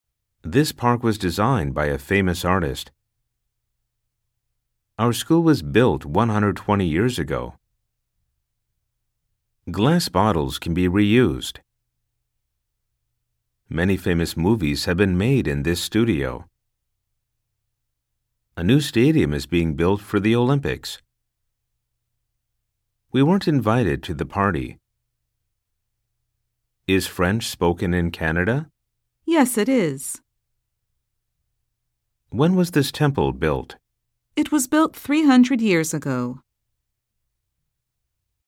Target例文 high speed　サンプル音声（mp3）